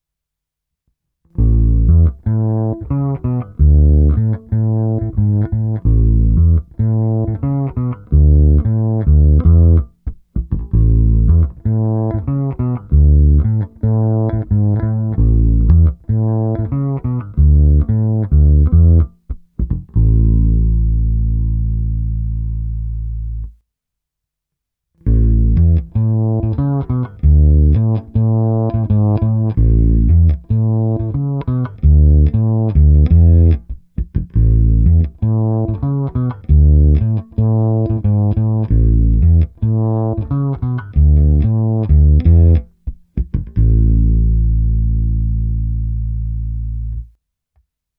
Hráno vždy blízko krku.
Nejvíc se mi líbil samostatný kobylkový snímač v humbucker módu, případně se zařazením pieza v poměru 50/50, což jsou dvě části následující ukázky. Všechny korekce na 1/2 a prohnal jsem to přes Darkglass Harmonic Booster, TC Electronic SpetraComp a Microtubes X Ultra se zapnutou simulací aparátu.